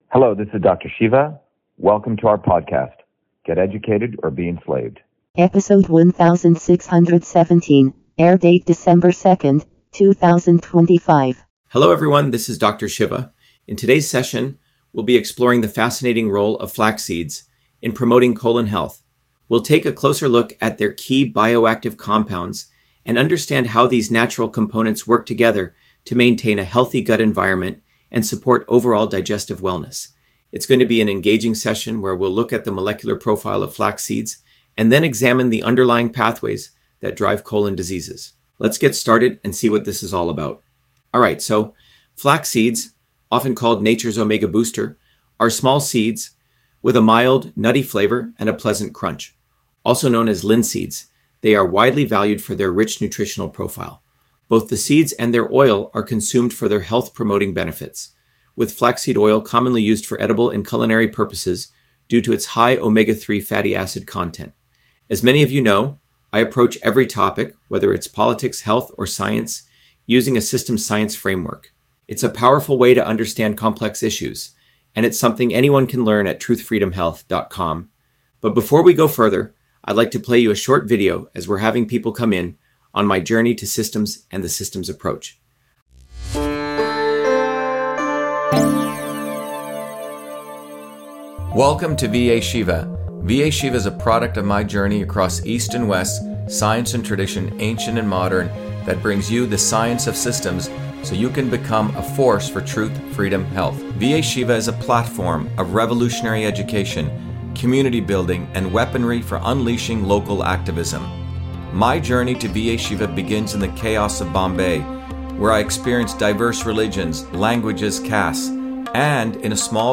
In this interview, Dr.SHIVA Ayyadurai, MIT PhD, Inventor of Email, Scientist, Engineer and Candidate for President, Talks about Flaxseeds on Colon Health: A Whole Systems Approach